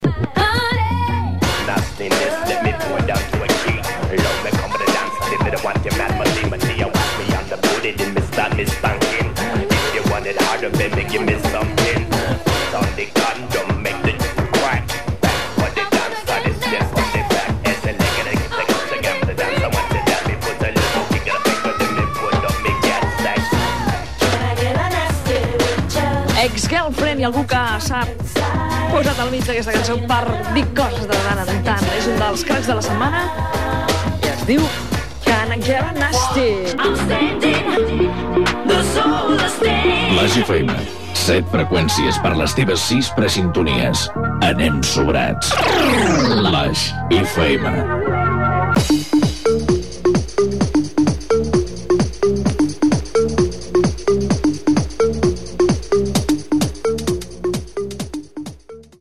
Tema musical i indicatiu de l'emissora